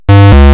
efek_salah.ogg